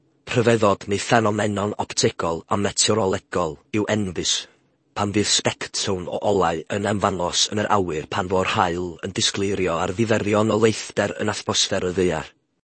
New multi-speaker Welsh voices